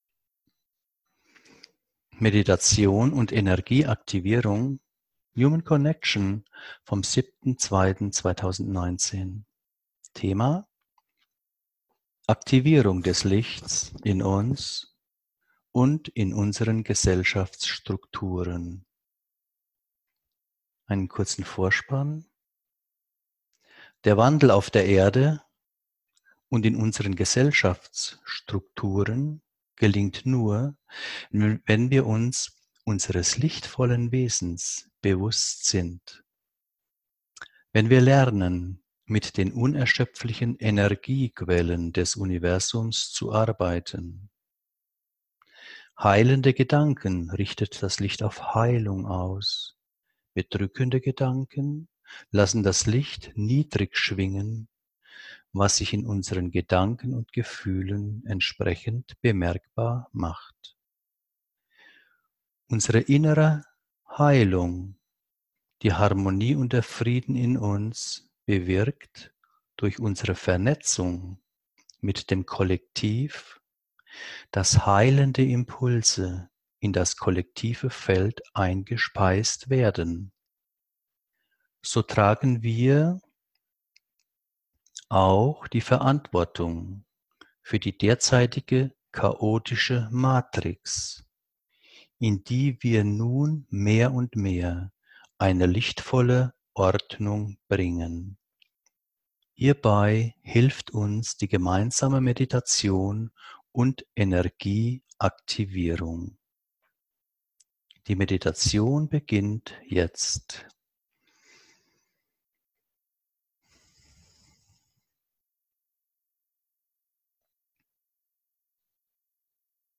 Diese geführte Meditation dient zur Aktivierung des Lichts in uns und in unseren Gesellschaftsstrukturen.